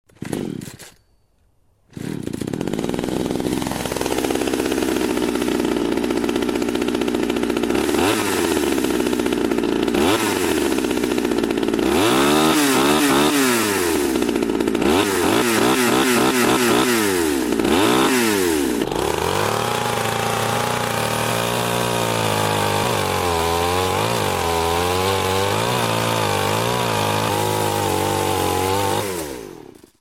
Бензопила - Сhainsaw
Отличного качества, без посторонних шумов.